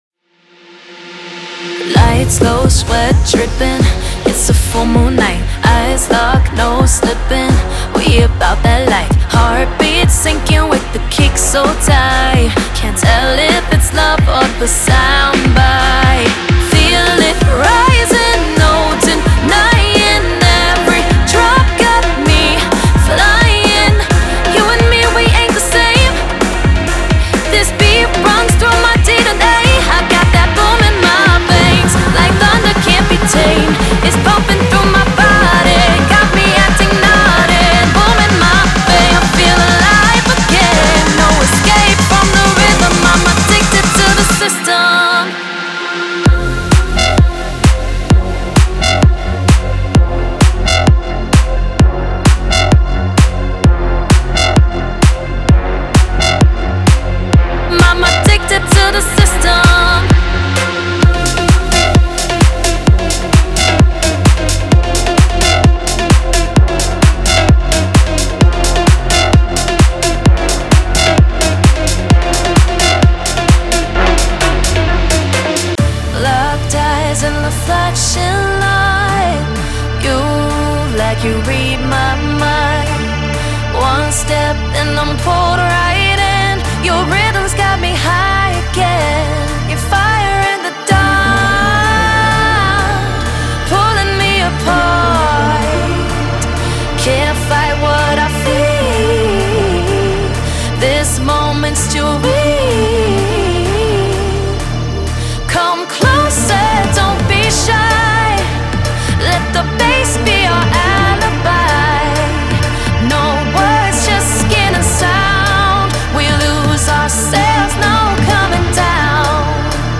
House Vocals
5 Acapella Vocals(Dry and Wet)